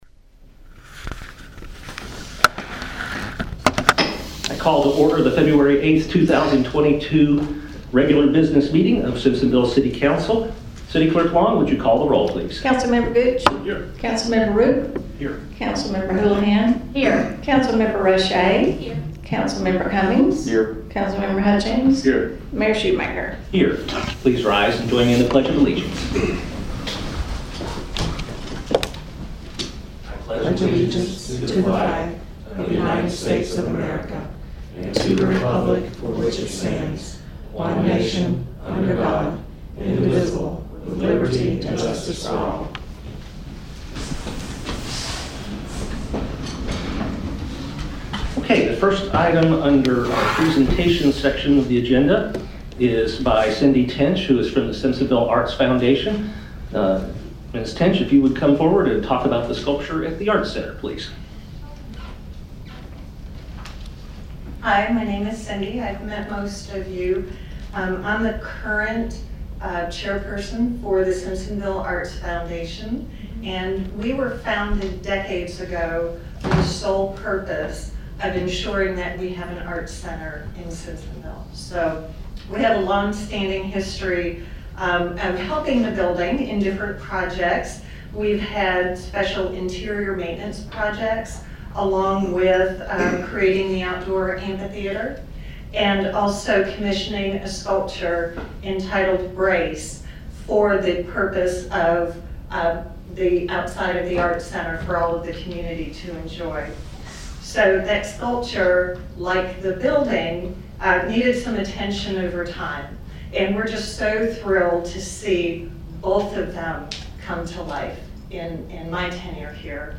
City Council Business Meeting